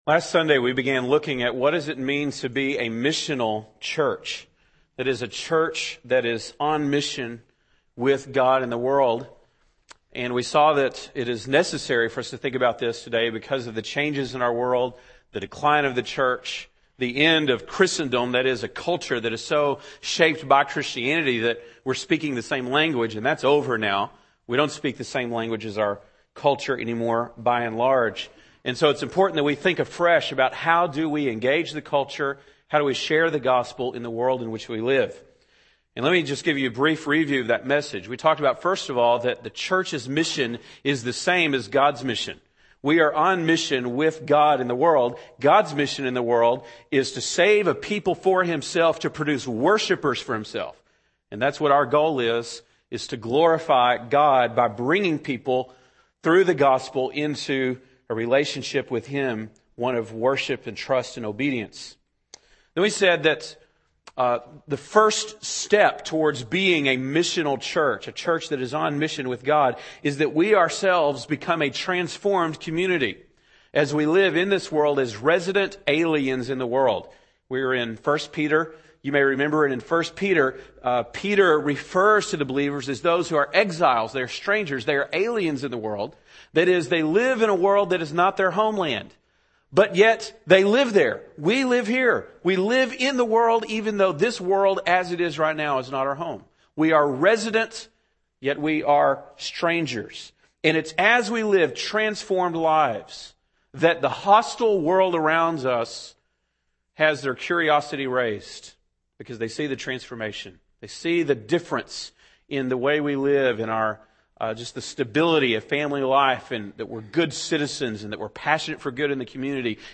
February 3, 2008 (Sunday Morning)